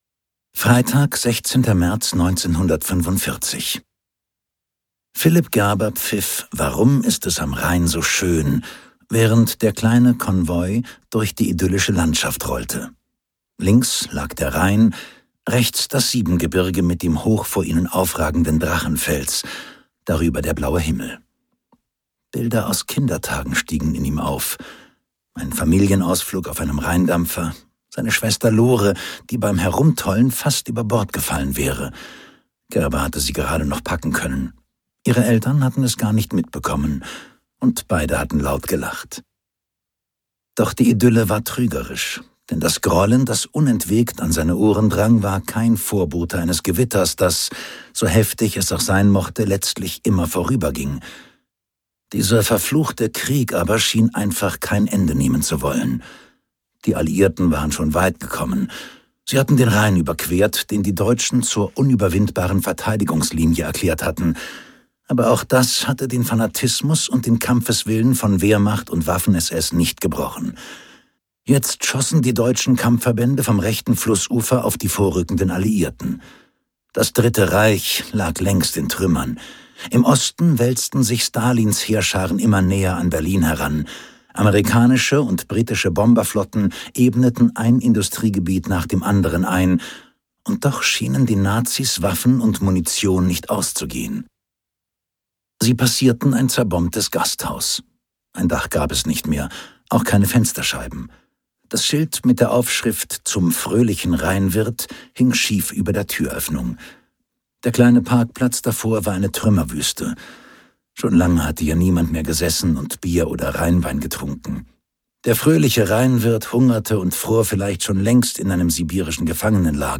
Historischer Thriller